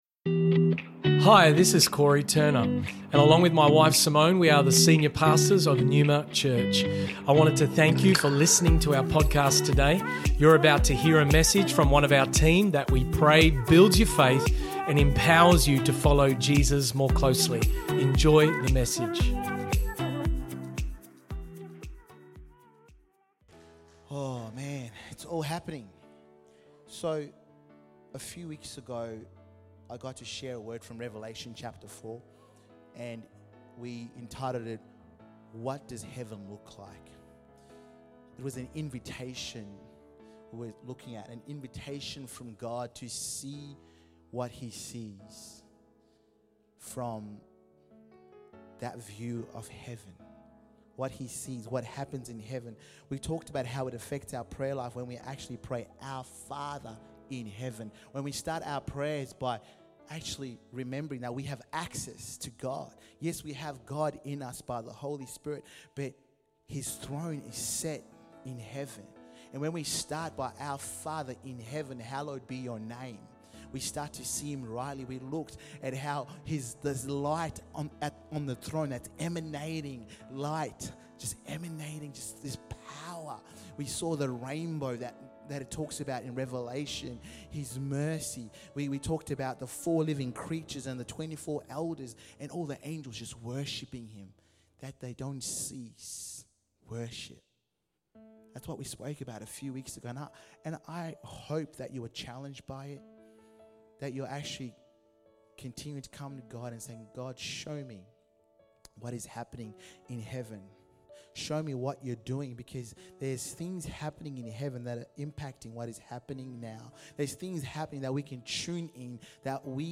Neuma Church Melbourne South Originally Recorded at the 10AM Service on Sunday 10th December 2023